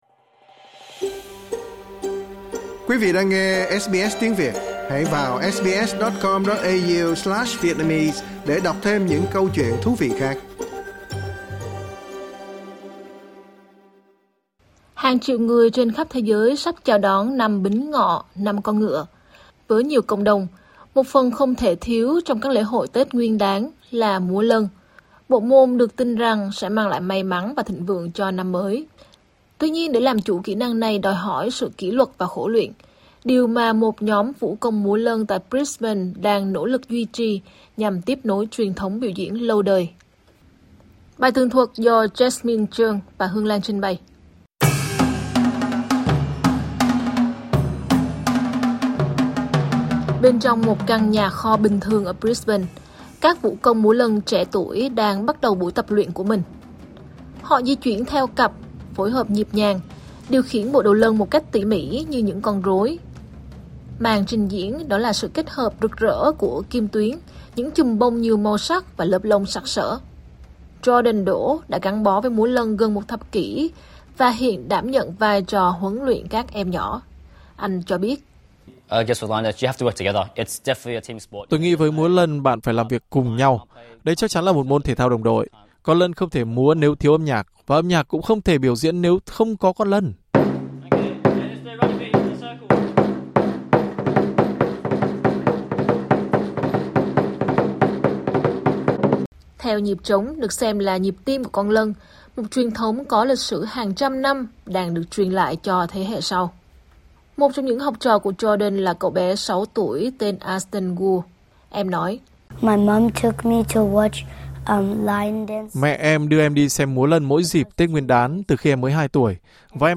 Rộn ràng nhịp trống múa lân ở Brisbane trước thềm Năm Bính Ngọ: Khi truyền thống được tiếp nối